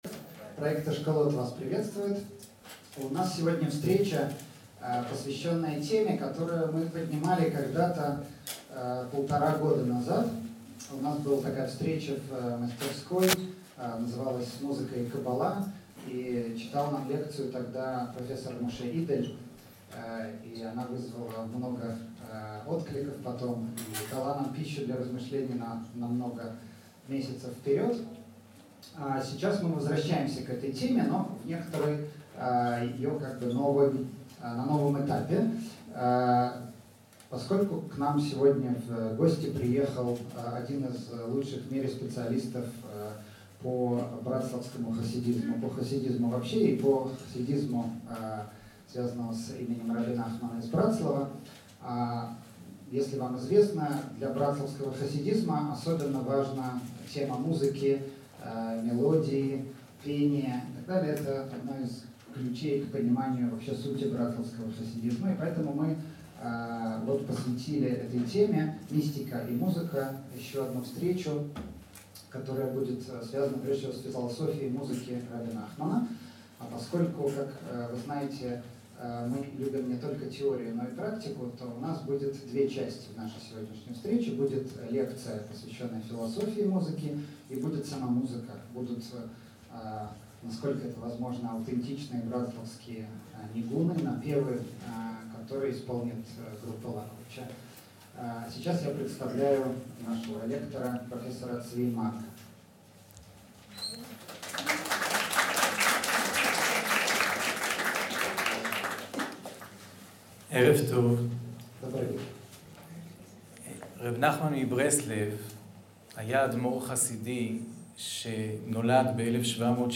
Аудиокнига Философия музыки в брацлавском хасидизме | Библиотека аудиокниг